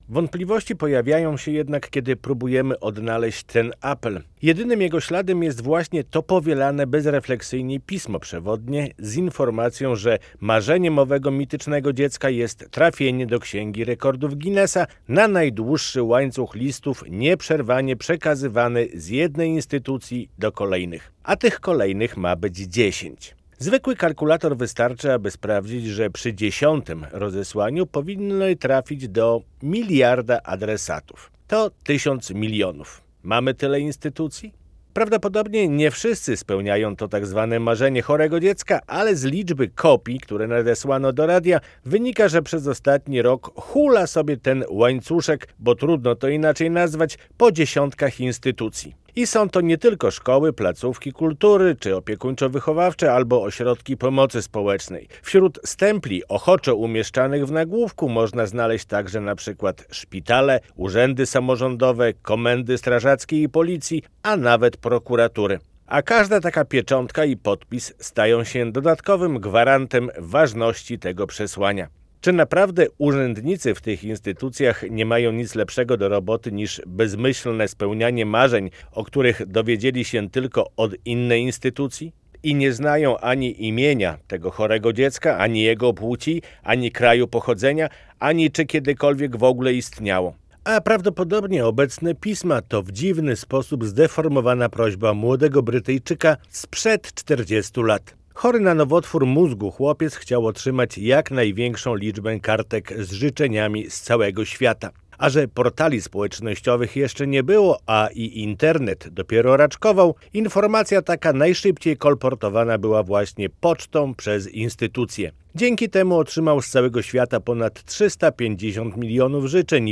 Radio Białystok | Felieton | Łańcuszek św.